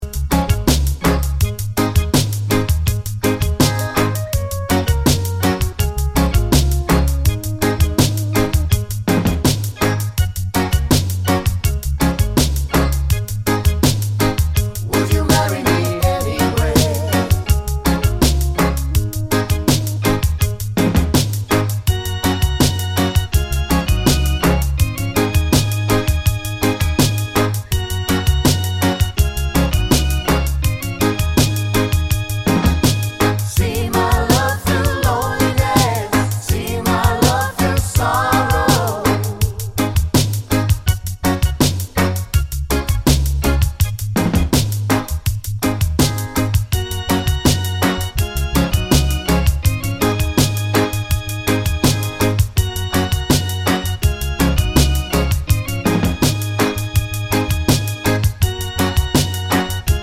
no Backing Vocals Reggae 3:36 Buy £1.50